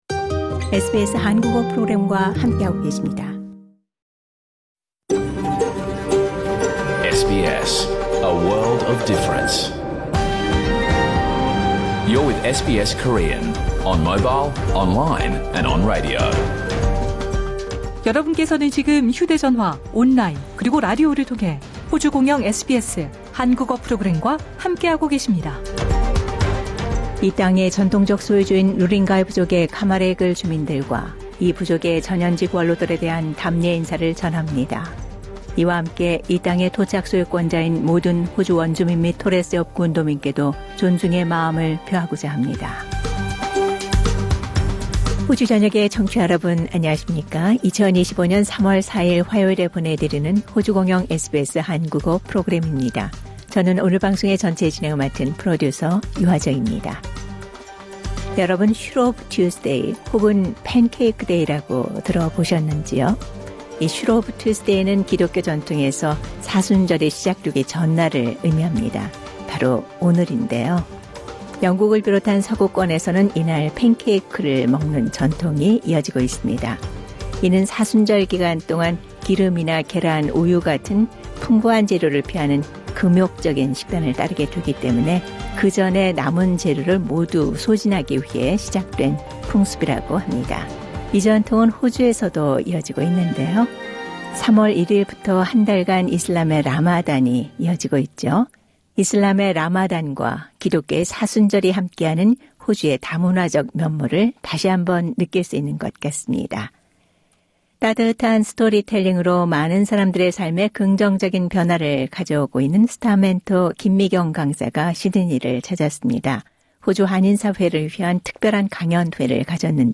2025년 3월 4일 화요일에 방송된 SBS 한국어 프로그램 전체를 들으실 수 있습니다.